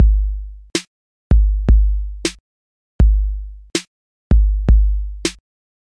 基础808鼓循环
描述：简单的HipHop 808大鼓
Tag: 80 bpm Hip Hop Loops Drum Loops 1.01 MB wav Key : Unknown